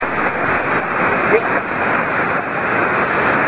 ちなみに移動地は清水市駒越海岸。
SP　SAMPLE-2　DPでの受信　(REAL AUDIO)
SAMPLE-2では、５エレの方が安定かつ強力に聞こえます。DPでは、途中一瞬だけ信号が確認できるだけです。